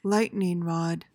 PRONUNCIATION:
(LYT-ning rod)